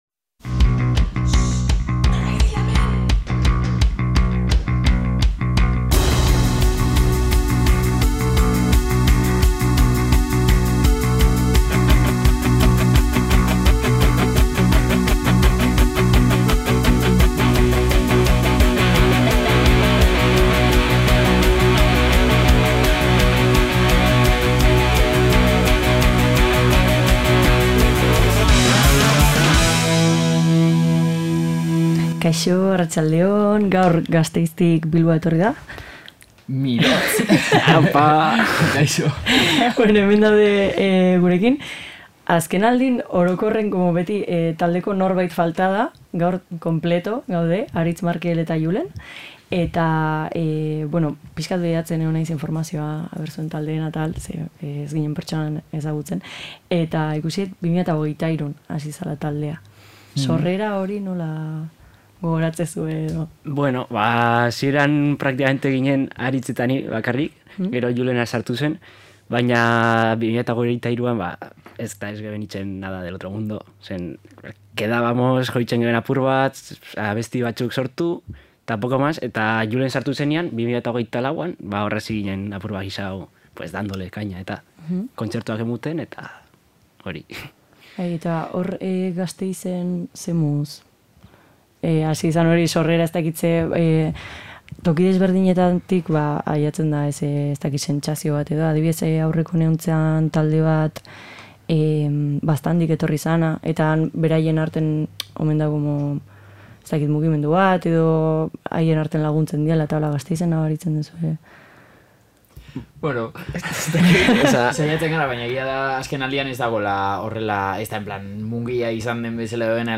Hirukote jator honekin batera saio benetan dinamikoa irten da eta oso ondo pasa dugu irratian.